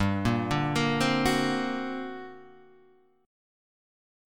G Minor Major 11th